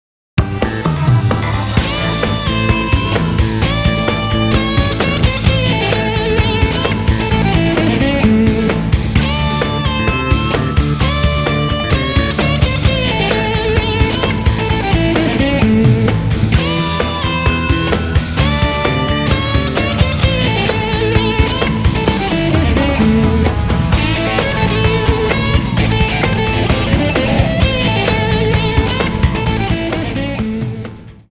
130 BPM